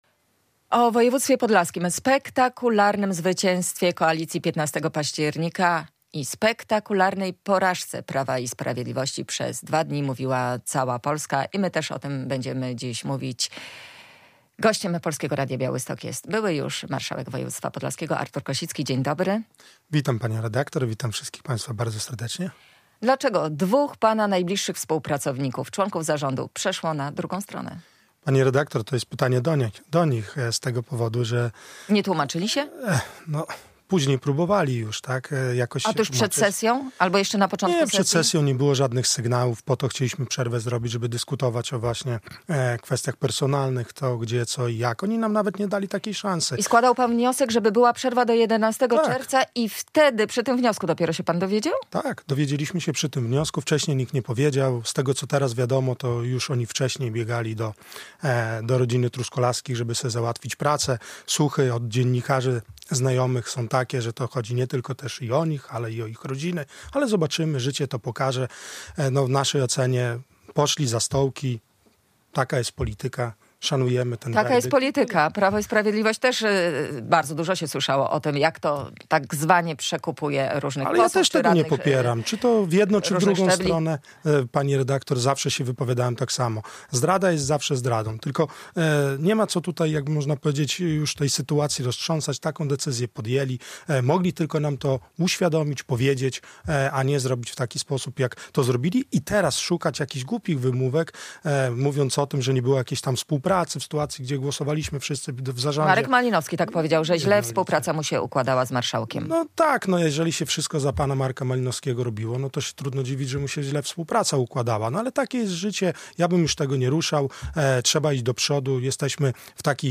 "Marszałkiem się bywa, a człowiekiem trzeba być. Dlatego dziękuję za wszystkie słowa wsparcia. Za - nie tylko to poparcie - 23 tys. głosów, które zostały na mnie oddane, ale też za ten odzew po tej sytuacji, która zaistniała we wtorek i zmianie w zarządzie" - mówił w Polskim Radiu Białystok były marszałek województwa podlaskiego Artur Kosicki.
studio